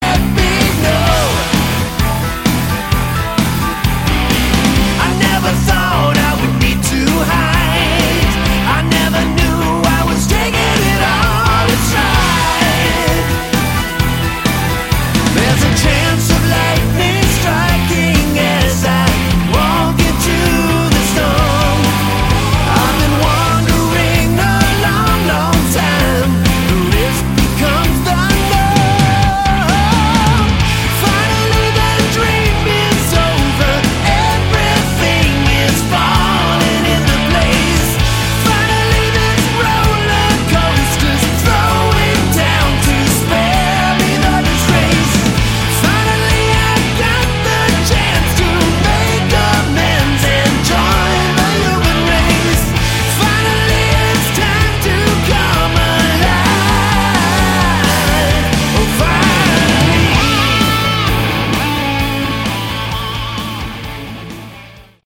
Category: Melodic Rock
bass, lead vocals
drums
guitars
keyboards
piano, additional keyboards